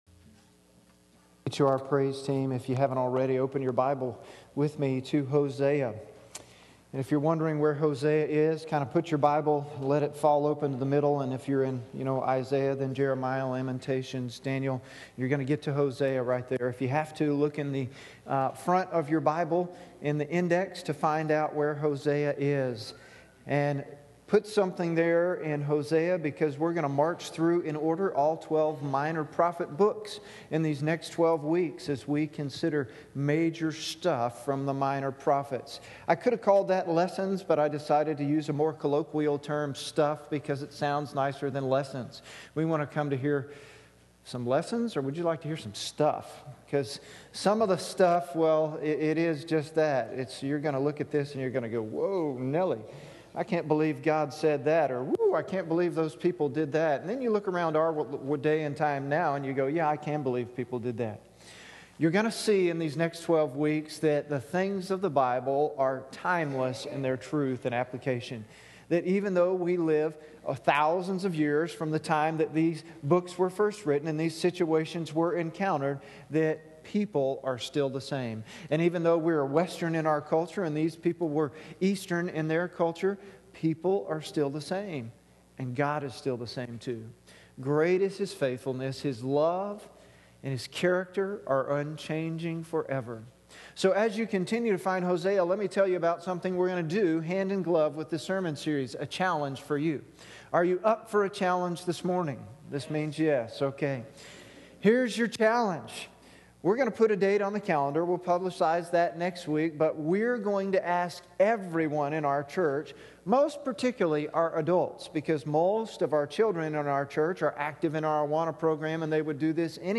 Editor’s Note: We had a technical issue at the 20:23 mark. It only lasted a moment, and the gap of silence has been edited to last 5 seconds int he podcast.